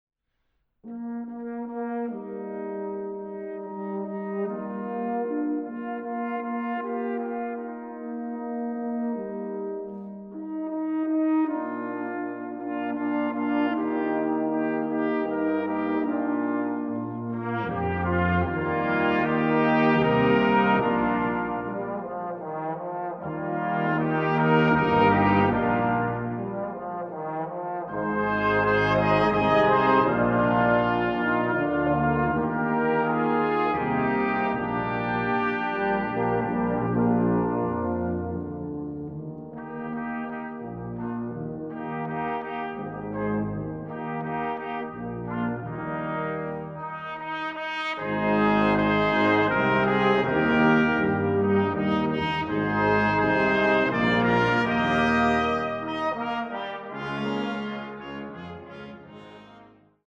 Musik für Posaunenchor und Blechbläserensemble